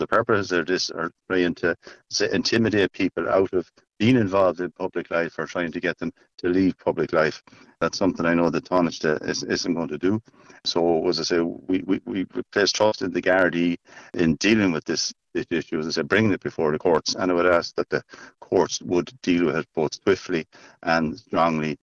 Chairperson of the Parliamentary Party TD Micheál Carrigy says this type of intimidation is unacceptable: